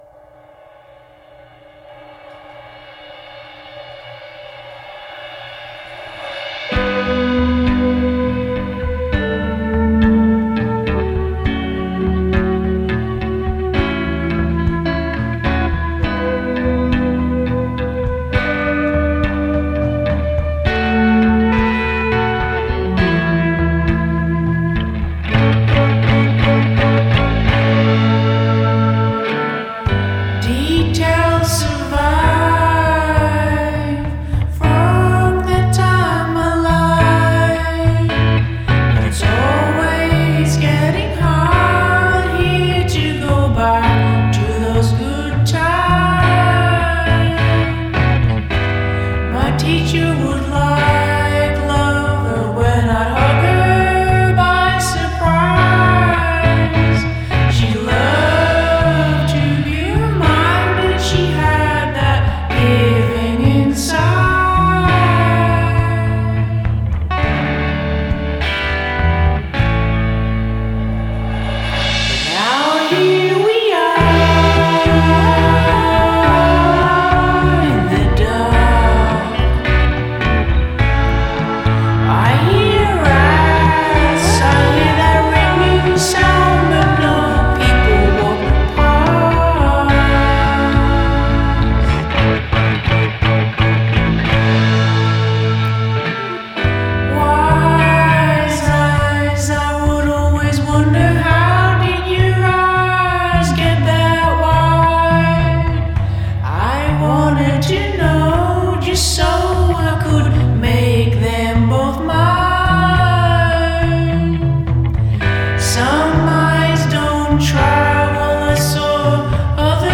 オルタナ感覚を活かしたサイケデリック～フォーク！